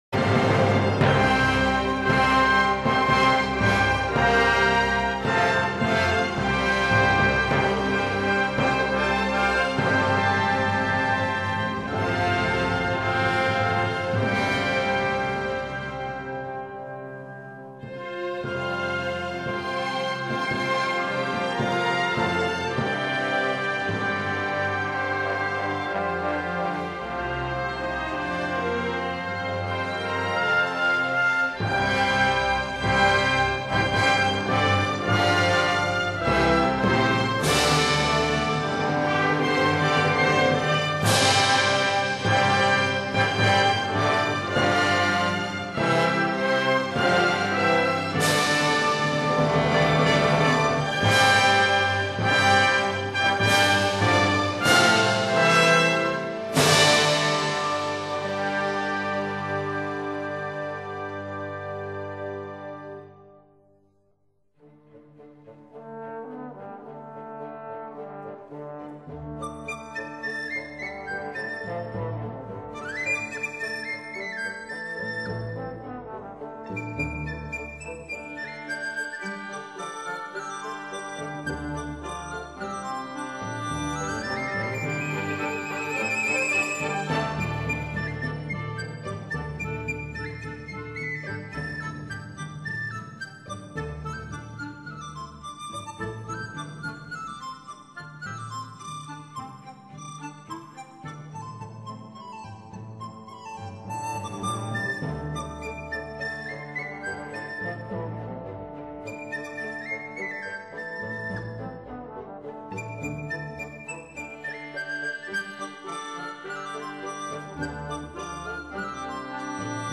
他的作品以西方音樂的架構，結合中國傳統音樂的精神內涵，開創出新一代中國音樂的風貌。